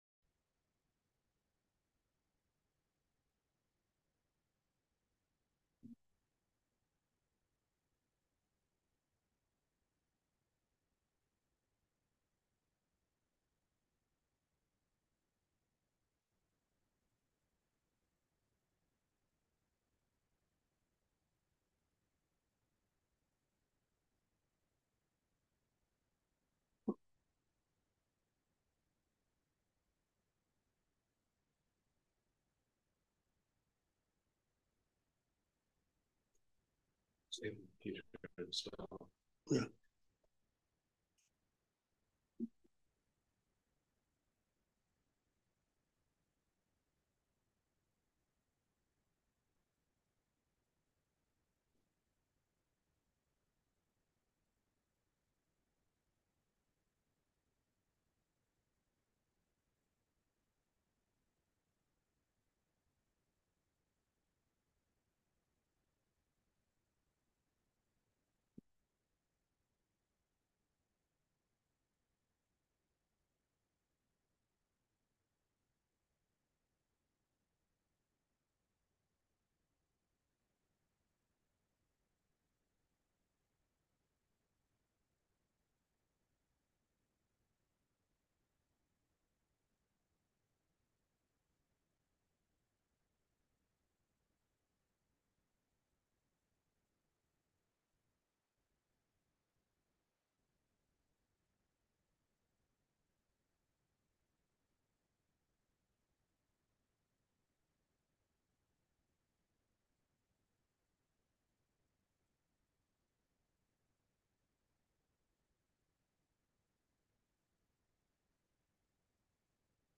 Passage: Colossians 1:10-11 Service Type: Sunday Evening Service